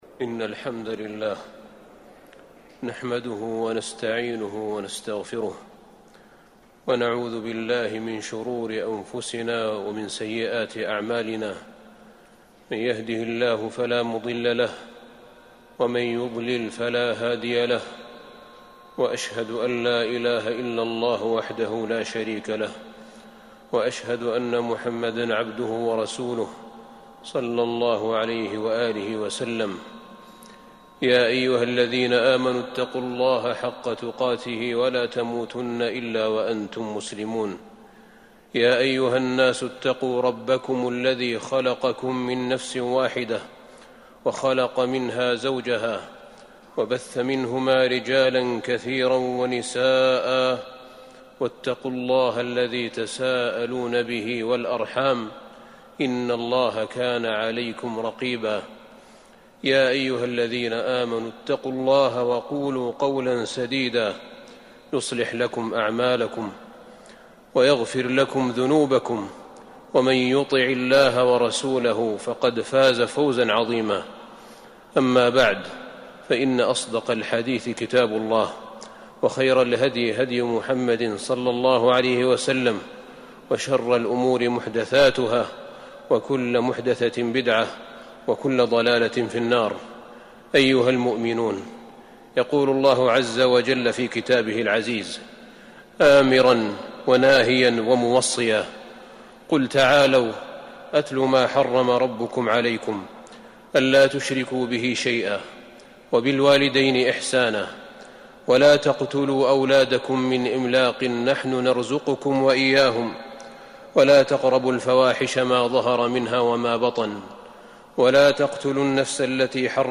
تاريخ النشر ١٥ صفر ١٤٤٢ هـ المكان: المسجد النبوي الشيخ: فضيلة الشيخ أحمد بن طالب بن حميد فضيلة الشيخ أحمد بن طالب بن حميد وصايا الله لأنبيائه وعموم خلقه